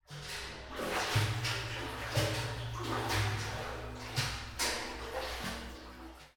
waterpullingoutfrombucket.ogg